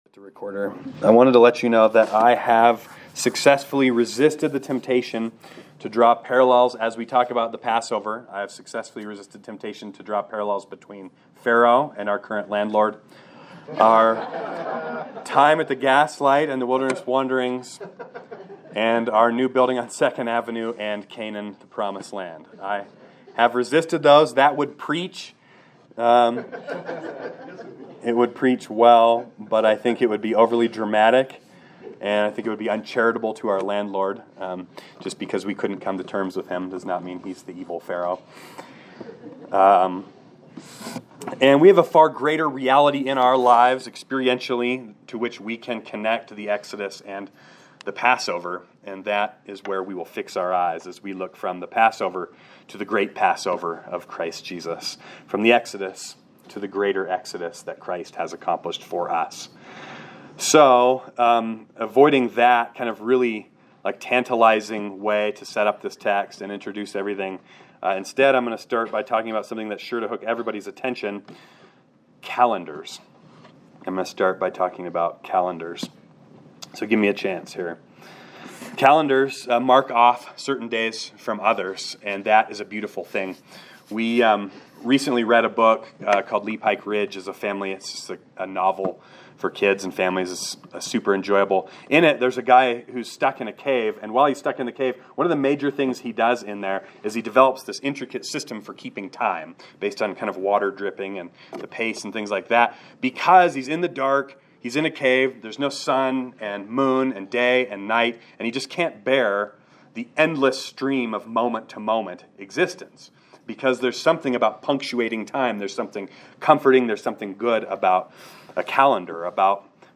Sermon Series: The Gospel of Mark